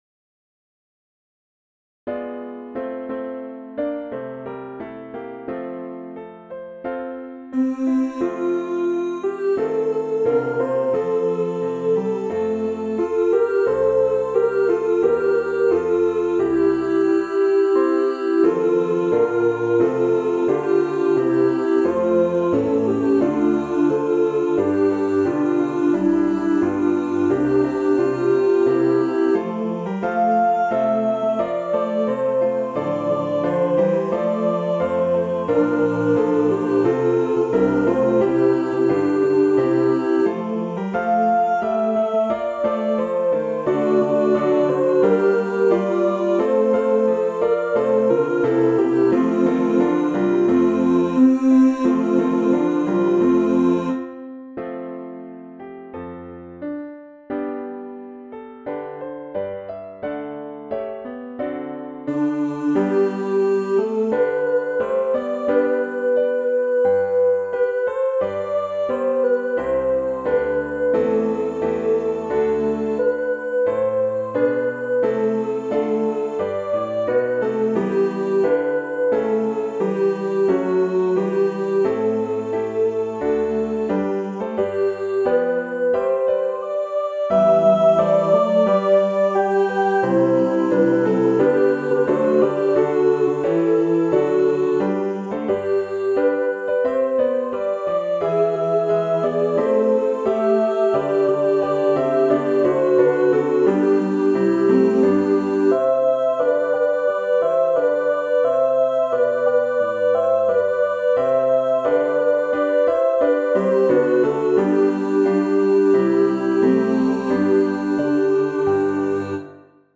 SAB and Piano
Choral
Anthem
Church Choir